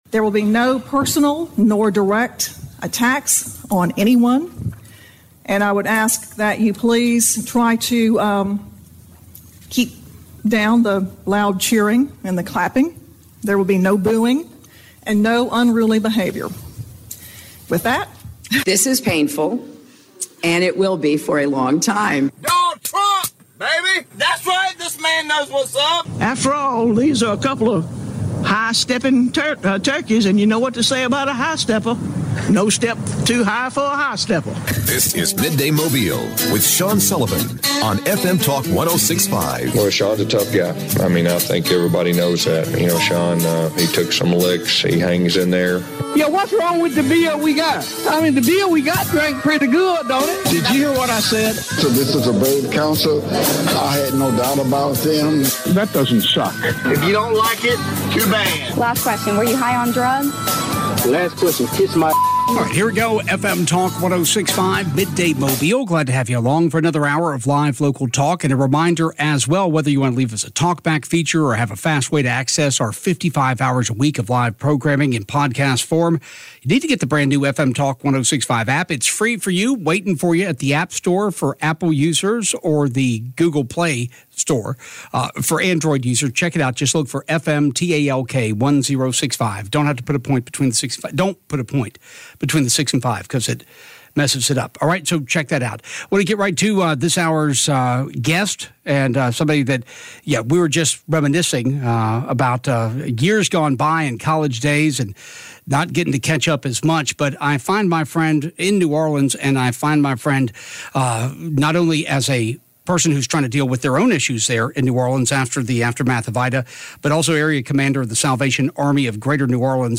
Listen to their conversation on our podcast